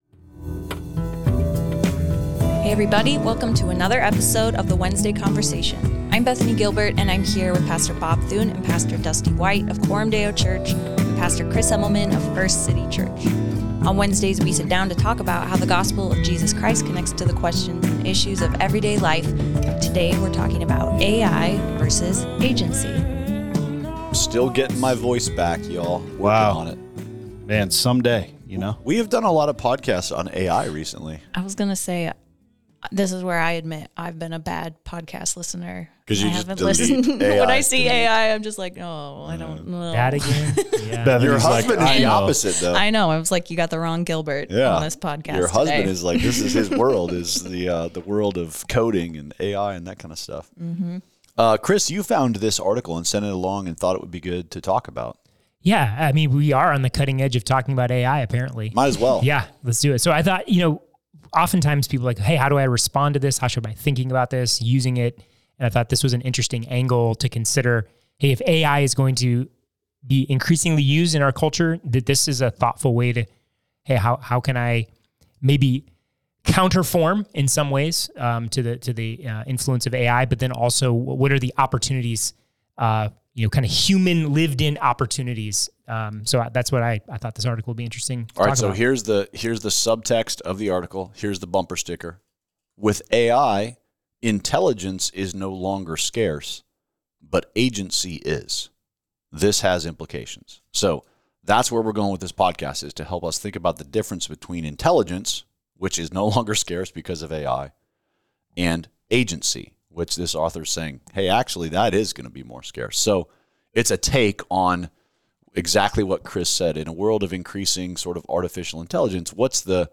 A weekly conversation about how the gospel of Jesus Christ connects to the questions and issues of everyday life. Hosted by the leaders of Coram Deo Church in Omaha, Nebraska.